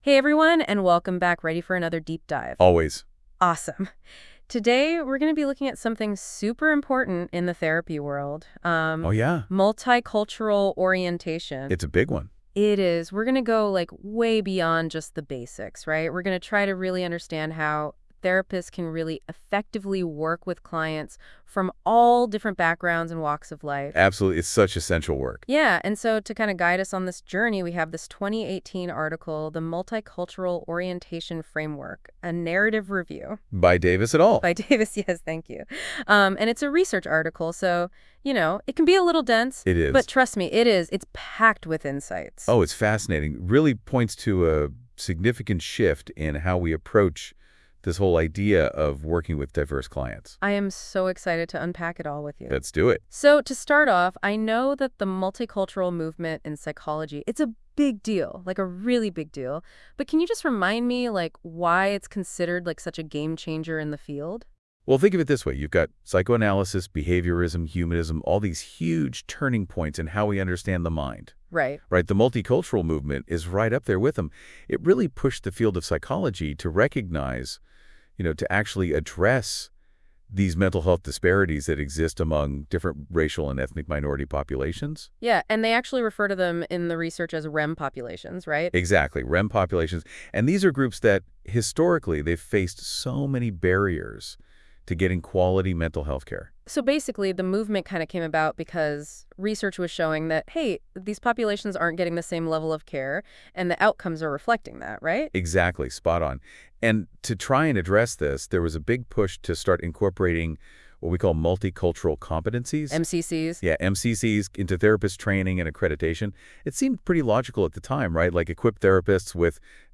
This podcast was generated by Notebook LM and reviewed by our team, please listen with discretion.